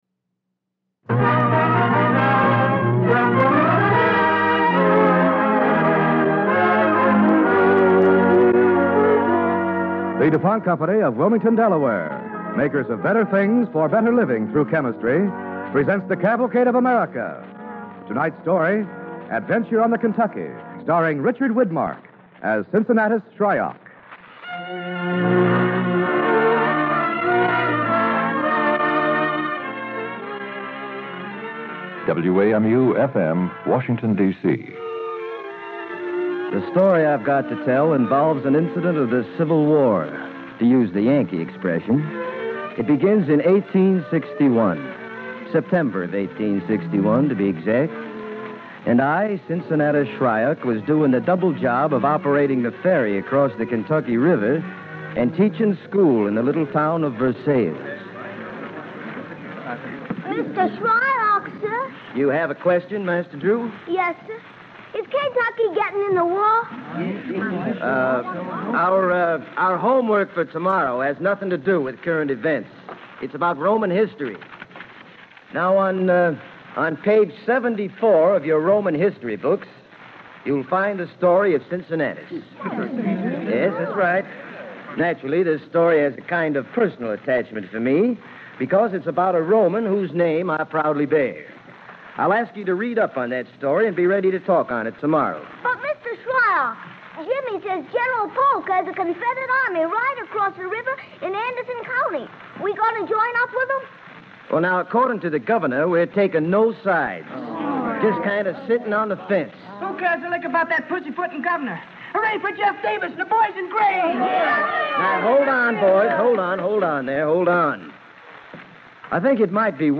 Adventure on the Kentucky, starring Richard Widmark and Dick York